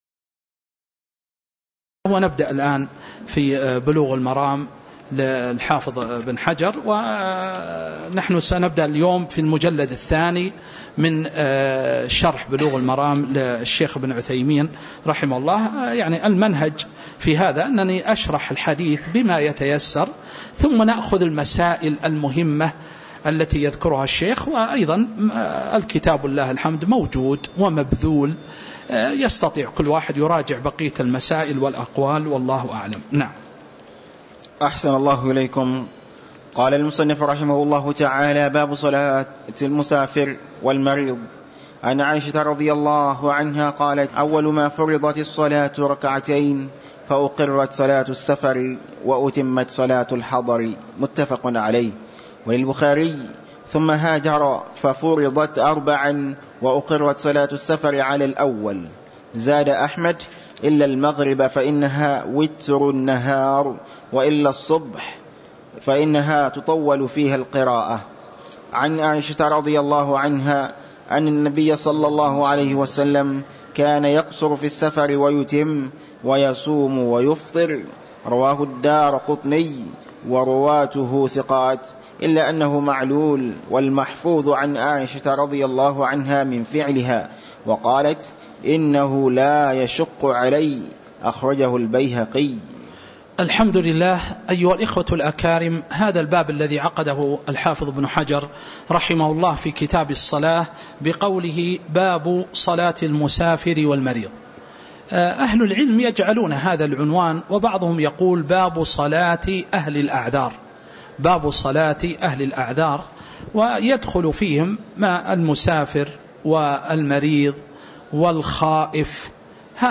تاريخ النشر ١ رجب ١٤٤٥ هـ المكان: المسجد النبوي الشيخ